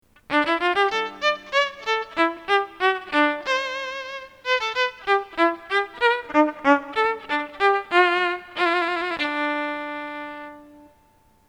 This recording also demonstrates the velocity waveform (Figure 4A) from a magnetic string sensing pickup (Figure 8), but demonstrates the timbre typical of the lower strings. Some reverberation, but no tone filtering, has been added to this recording.
3B_StringVelocity.mp3